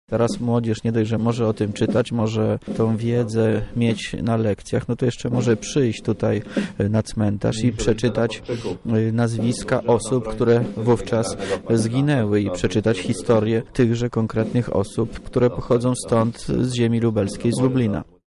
Żołnierze byli często chowani potajemnie, takie upamiętnienie pokazuje ich bohaterstwo – mówi Wojciech Wilk, wojewoda lubelski.